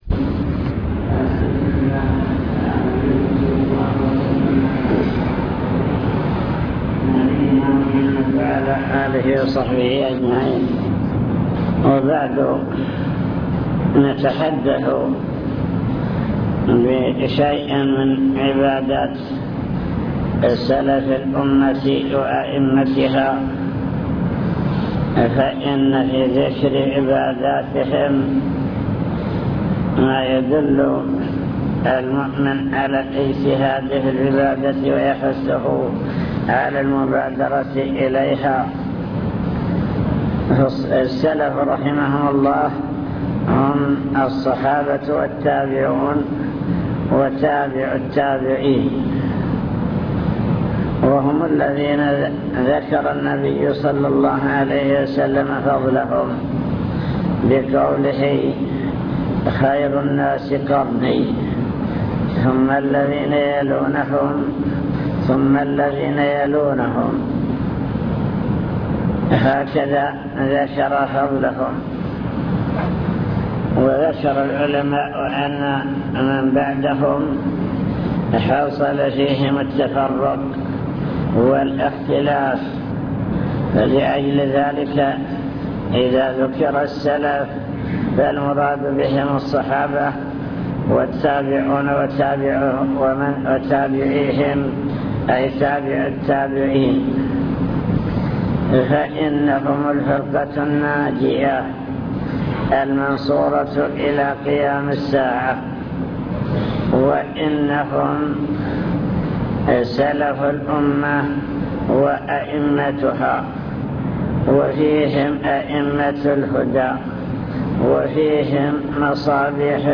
المكتبة الصوتية  تسجيلات - محاضرات ودروس  محاضرة في النصرية أحوال سلف الأمة في العبادة